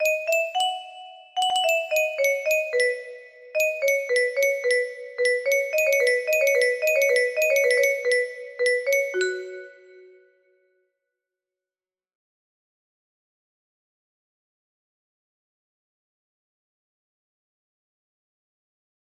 Sankyo mystery song 2 music box melody